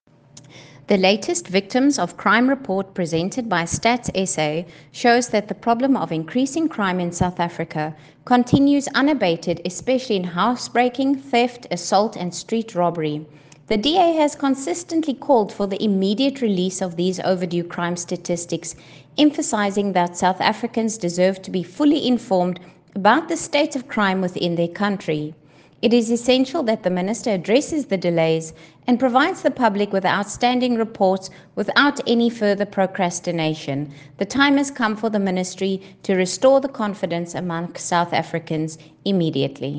soundbite by Lisa Schikerling MP